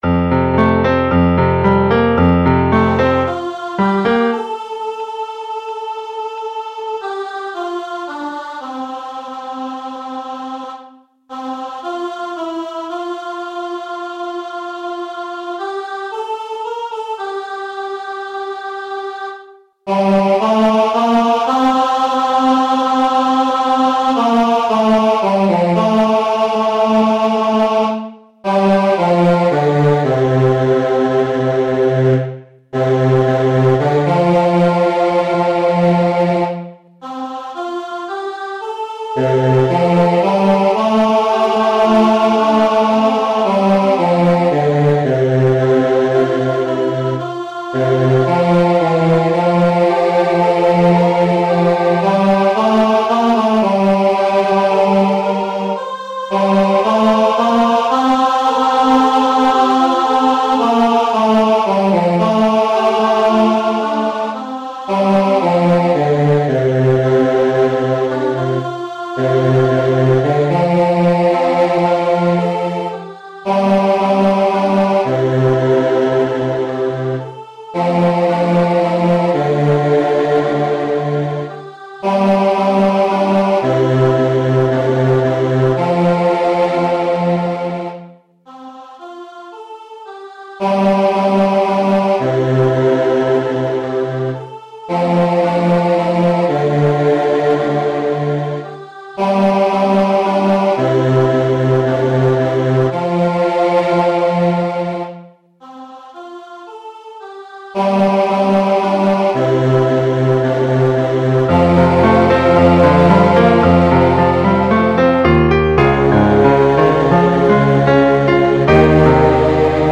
A_Round_Of_Thanks_14_Bass.MP3